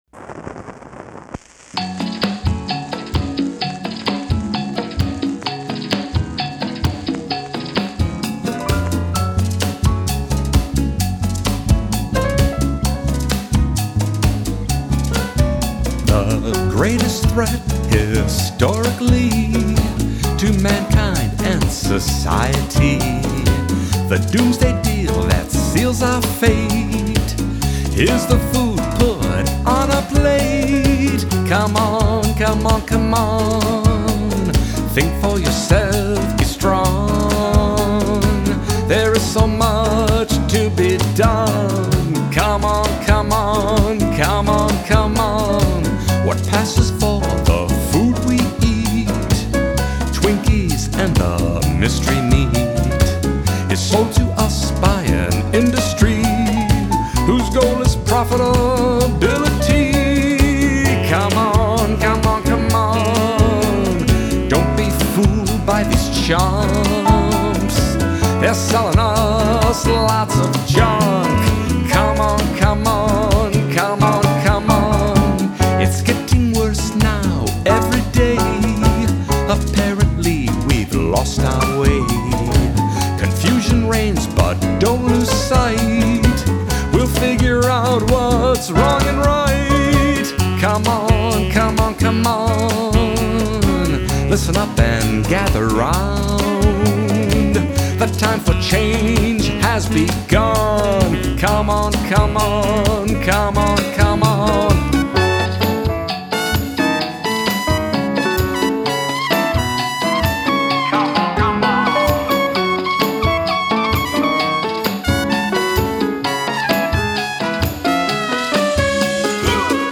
lead and back up vocals
Rhythm guitar